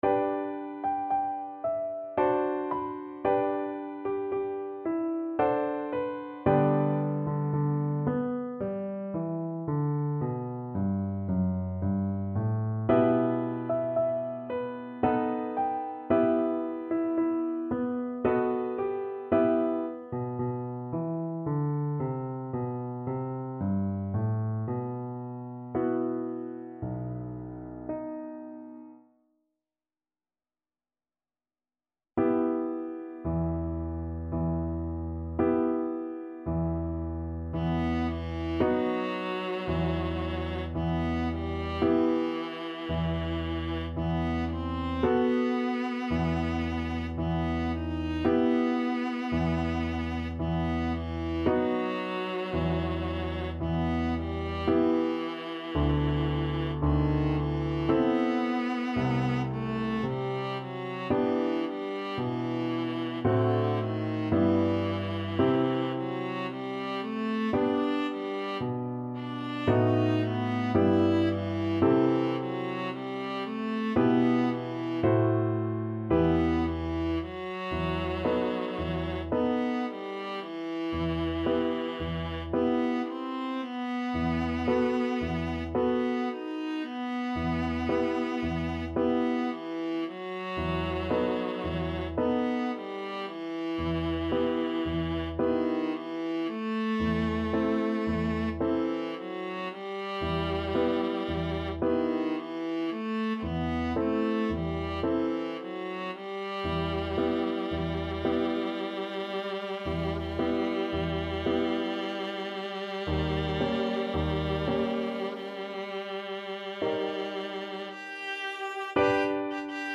3/4 (View more 3/4 Music)
~ = 56 Andante
Classical (View more Classical Viola Music)